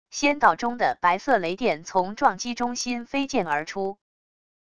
仙道中的白色雷电从撞击中心飞溅而出wav音频